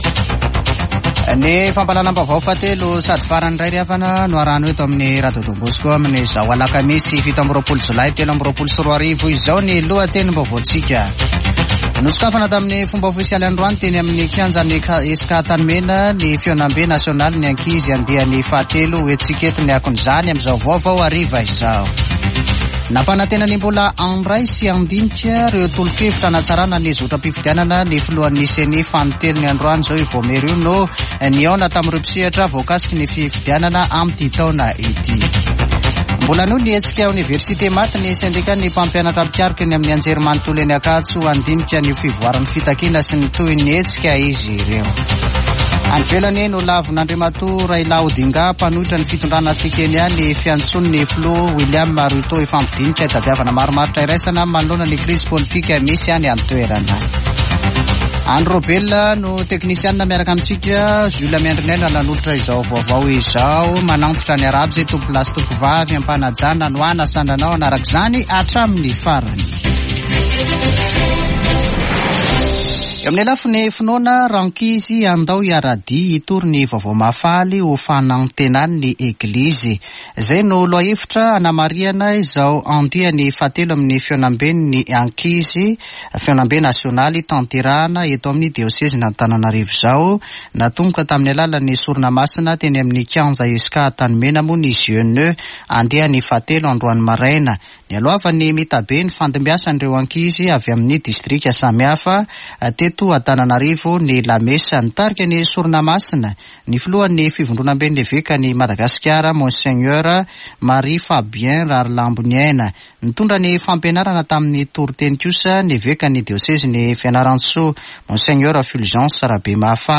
[Vaovao hariva] Alakamisy 27 jolay 2023